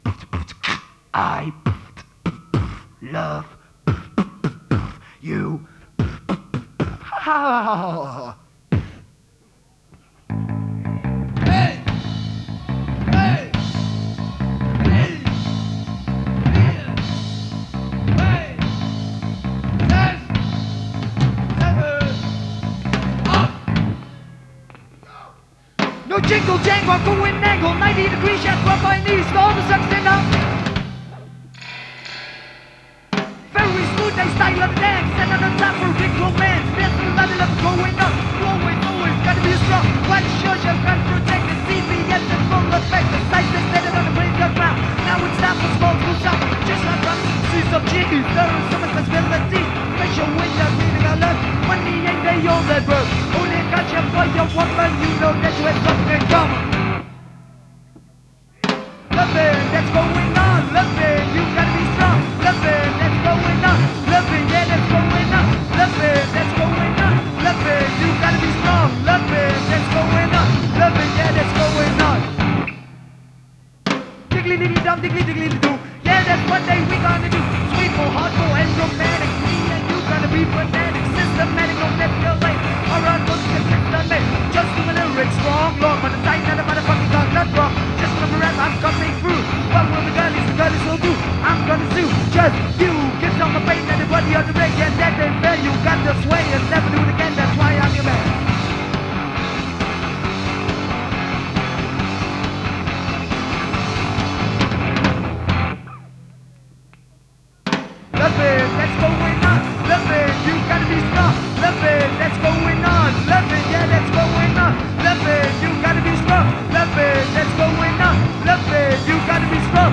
Live in Atak Enschede